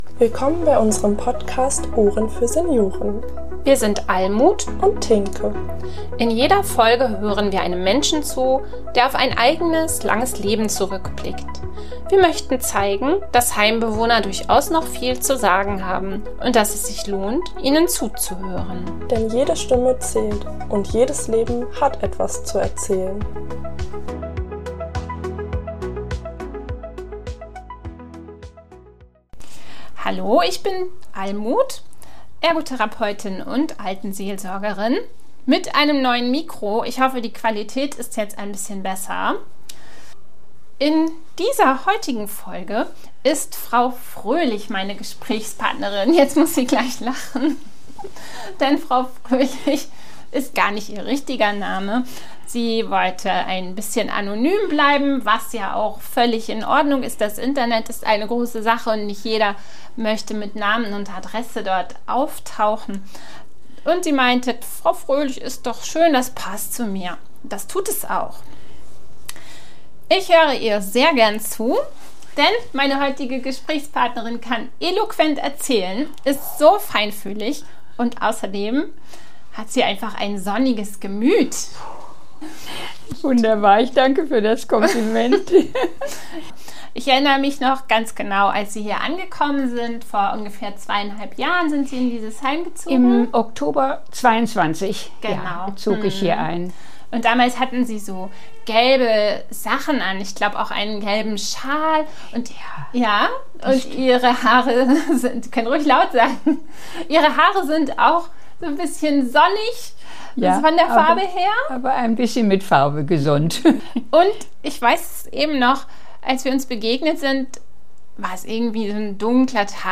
Vom Suchen und Finden der Freude, von der Bedeutung des Lächelns, des Lesens und des Lernens erzählt uns in dieser Folge eine junggebliebene 90-jährige Heimbewohnerin.